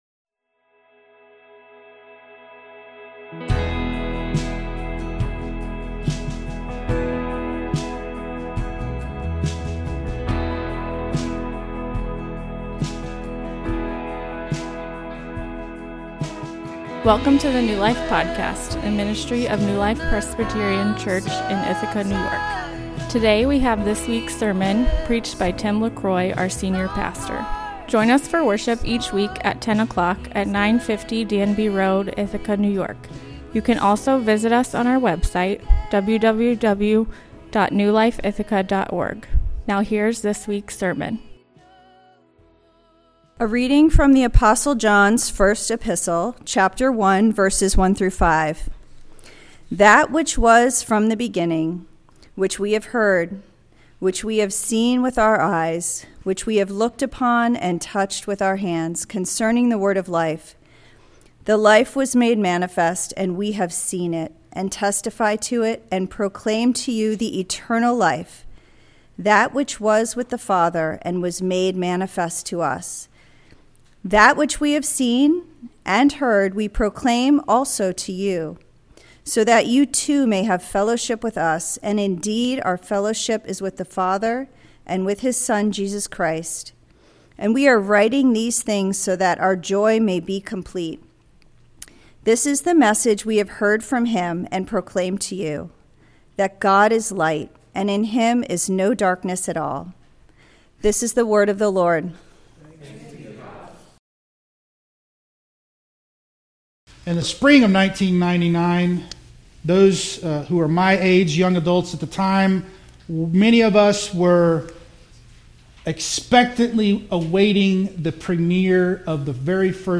Sermon Outline: 1. John asserts the authority of Apostolic eyewitness 2. John asserts the fact of the incarnation 3. John proclaims the source of life and joy